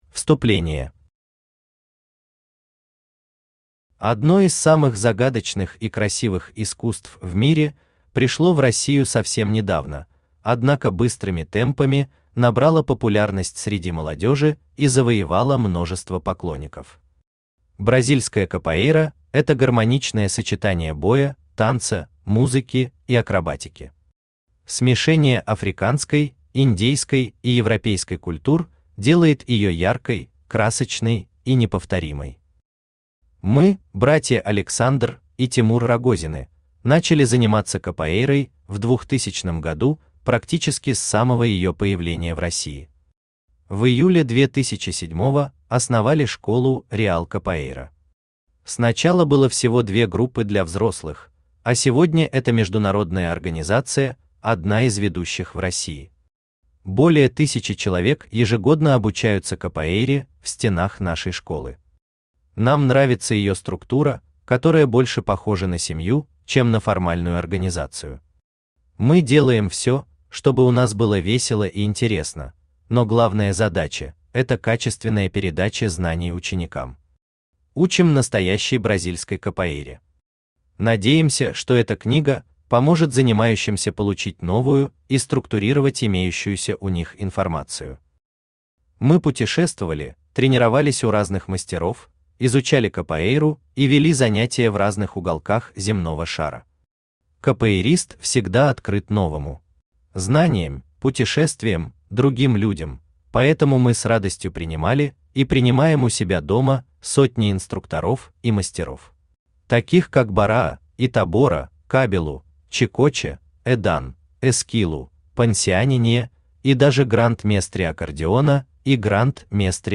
Введение Автор Александр Владимирович Рогозин Читает аудиокнигу Авточтец ЛитРес.